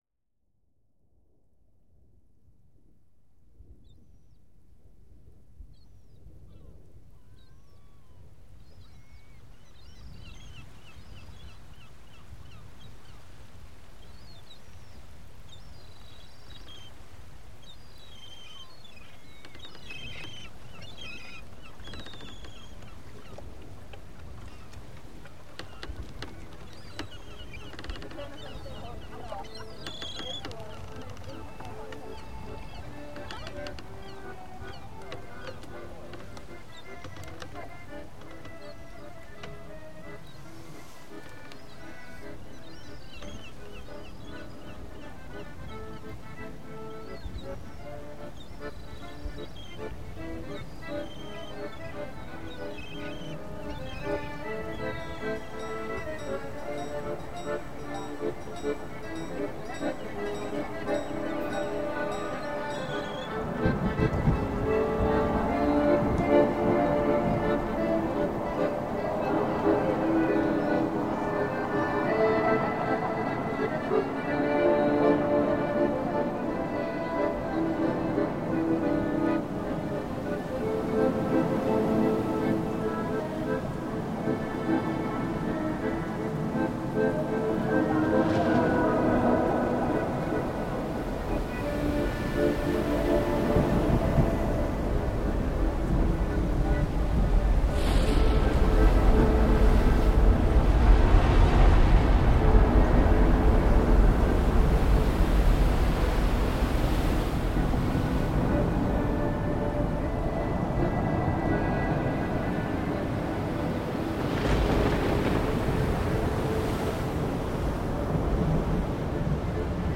Helsinki accordion busker reimagined